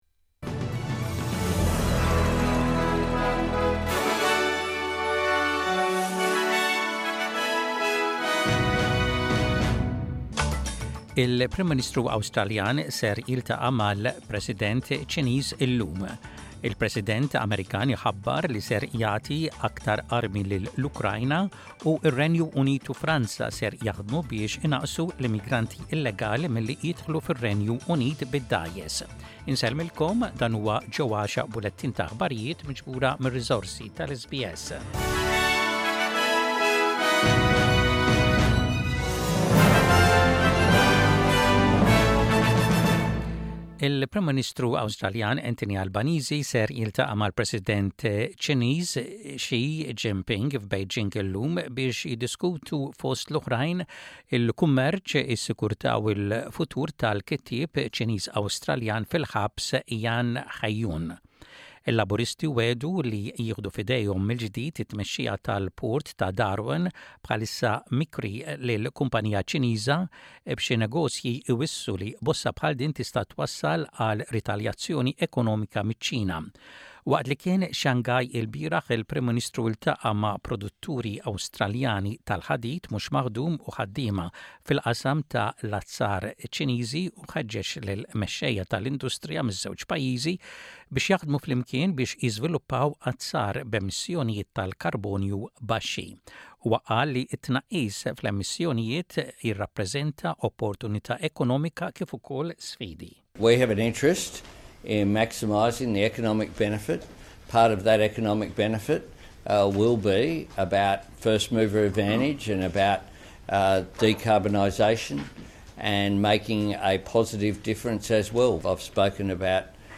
Aħbarijiet bil-Malti: 15.07.25